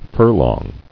[fur·long]